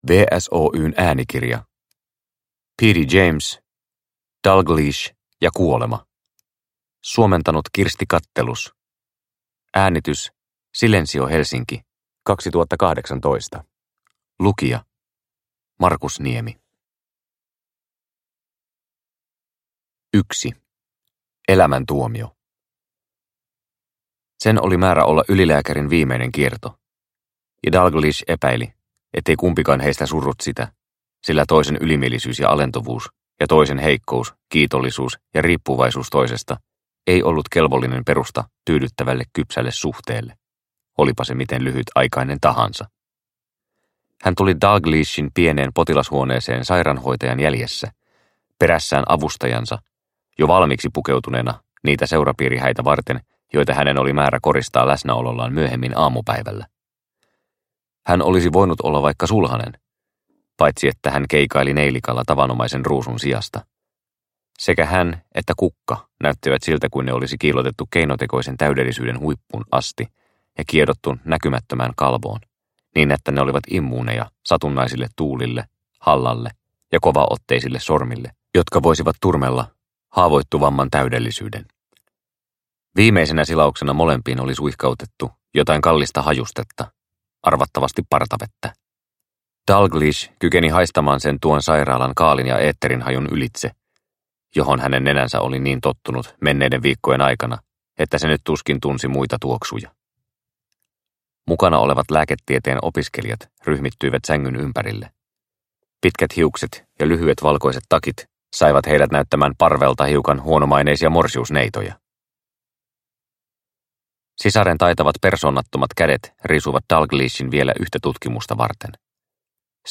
Dalgliesh ja kuolema – Ljudbok – Laddas ner